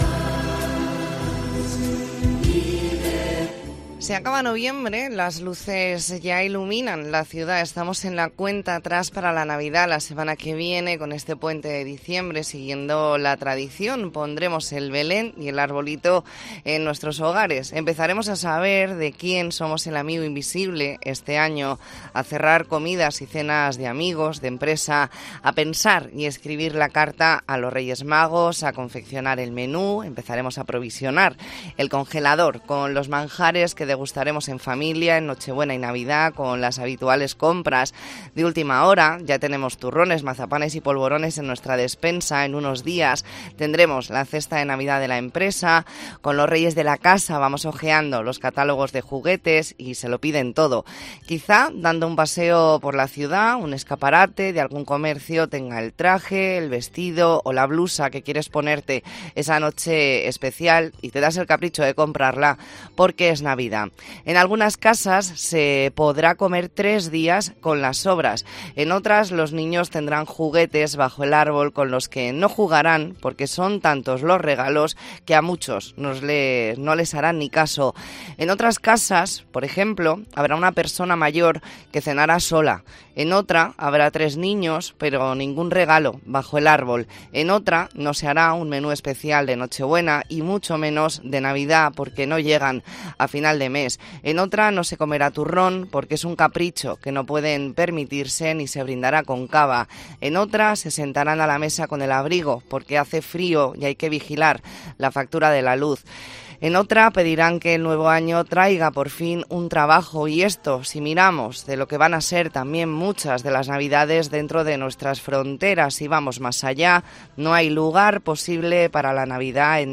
Entrevista en La Mañana en COPE Más Mallorca, miércoles 29 de noviembre de 2023.